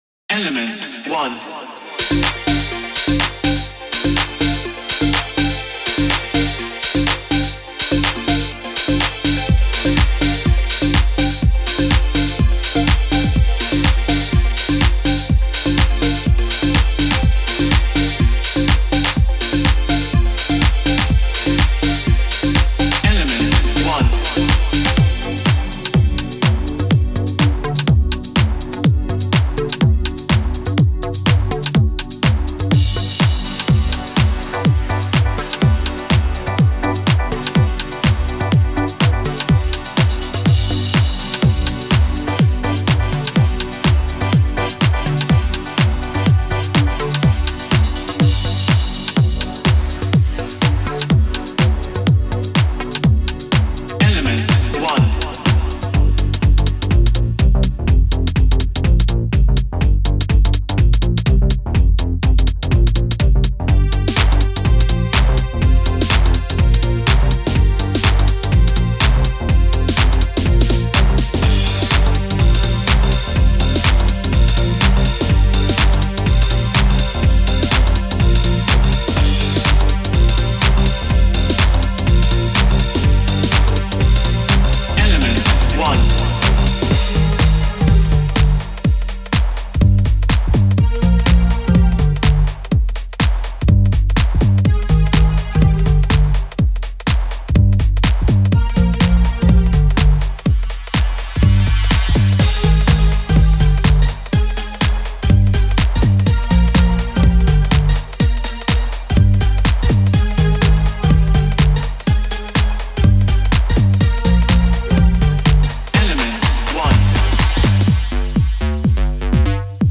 Genre:Deep House